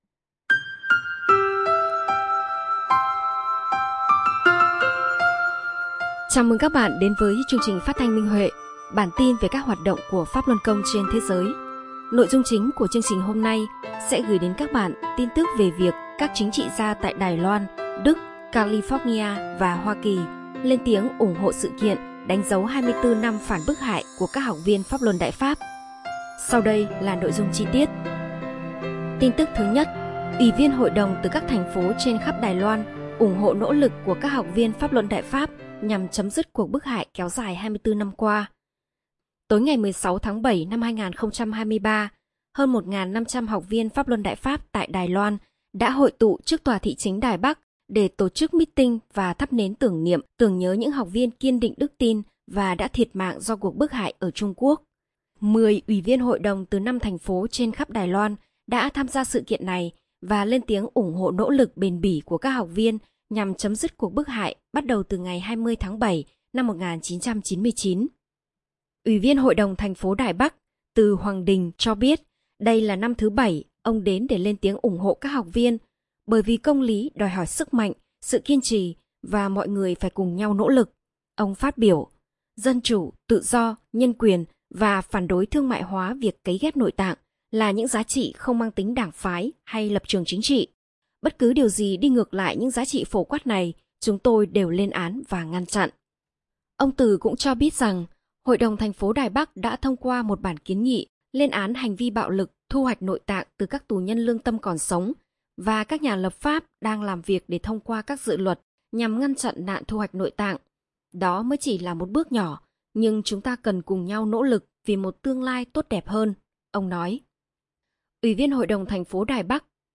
Chương trình phát thanh số 47: Tin tức Pháp Luân Đại Pháp trên thế giới – Ngày 24/7/2023